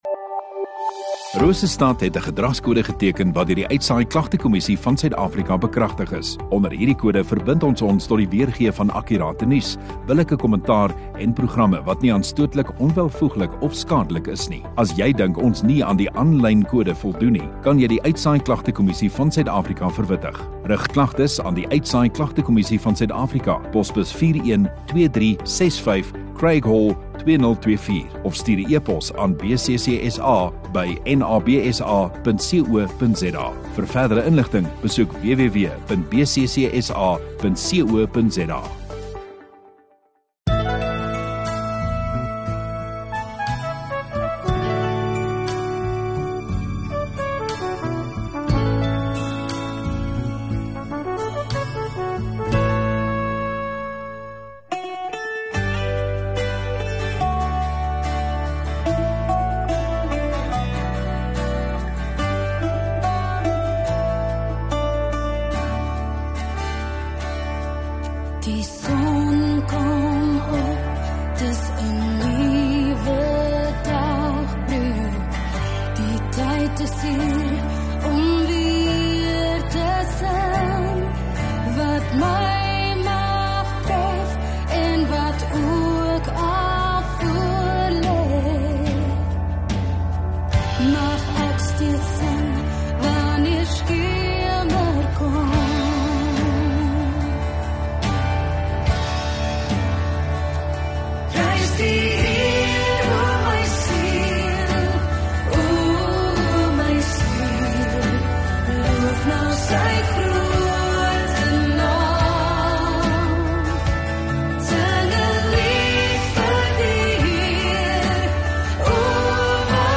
9 Aug Saterdag Oggenddiens